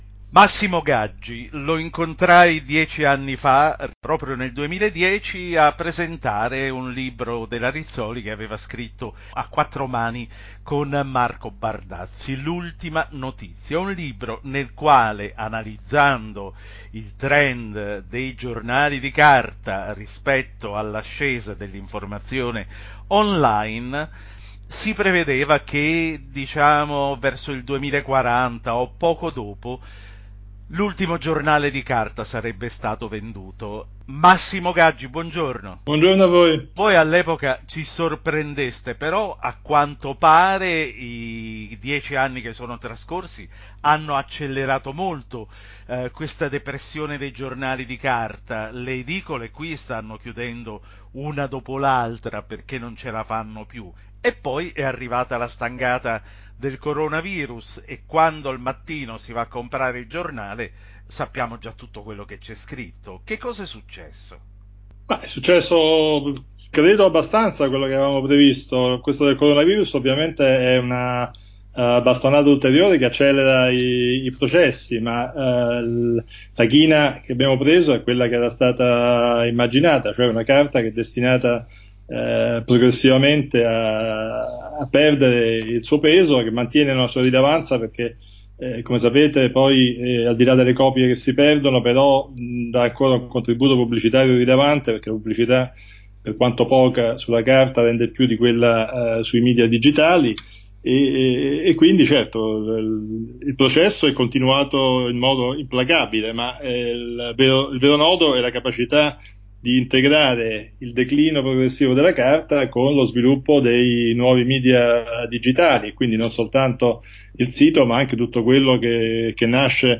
Che cosa succederà ai giornali dopo la pandemia? Chiacchierata